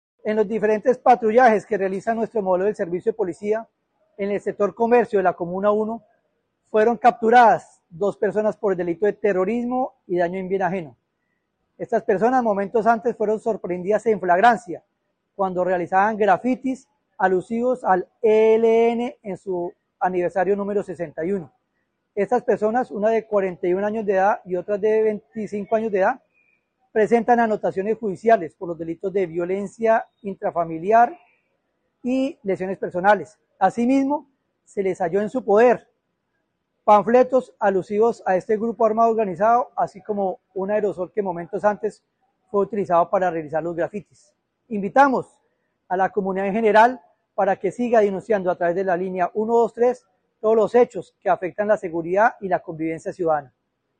Coronel Mauricio Herrera Comandante (e) Policía del Magdalena Medio